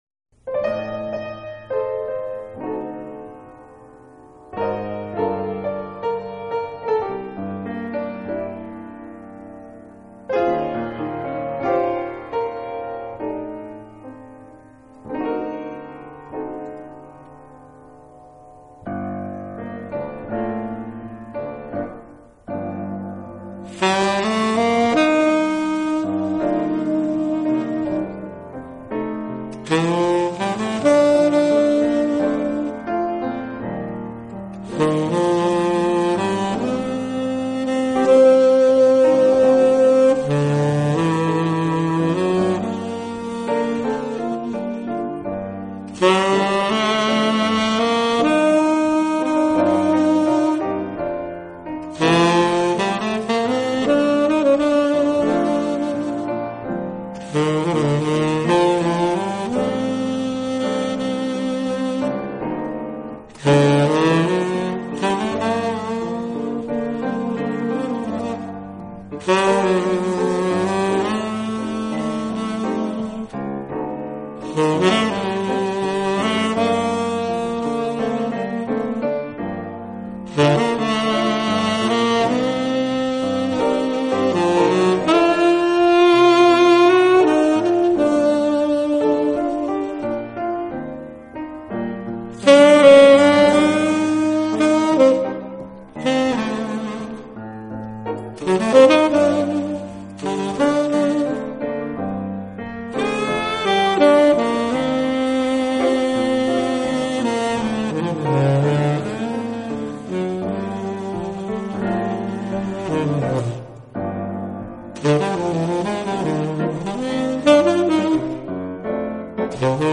整碟基本上都是萨克斯的Solo。
这张就是两种乐器 的录音----萨克斯和钢琴。
此 碟录音空气感十足，堂音丰富，把萨克斯的音色真实地还原了出来。